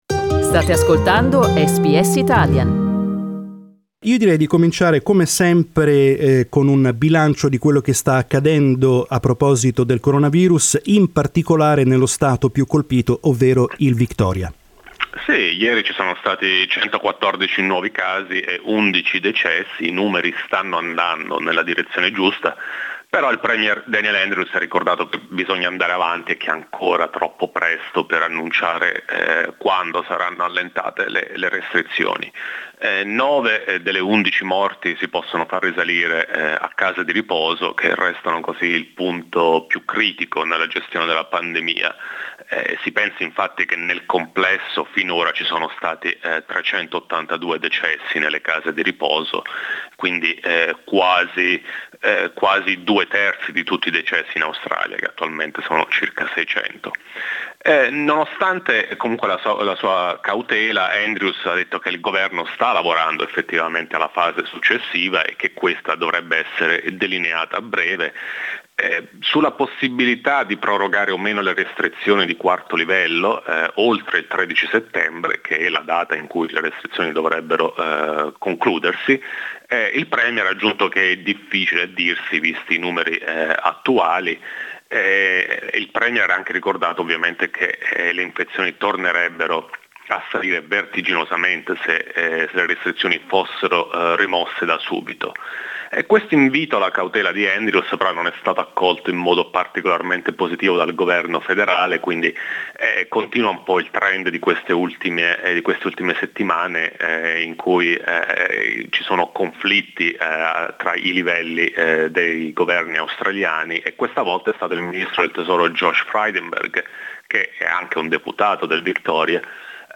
Ne abbiamo parlato con l'esperto di politica australiana